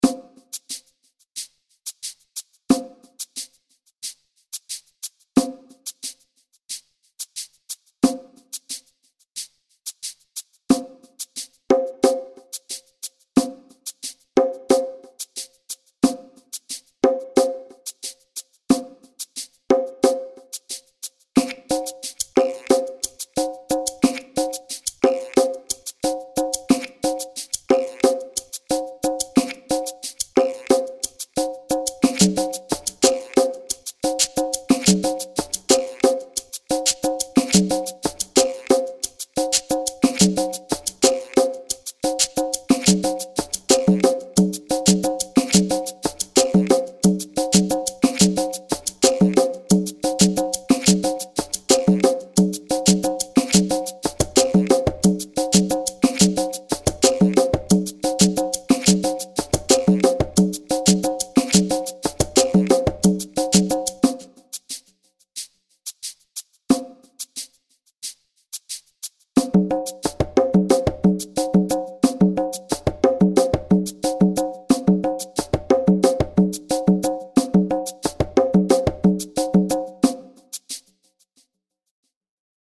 Secuencia desarrollada de percusión (bucle)
Música electrónica
percusión
repetitivo
rítmico
sintetizador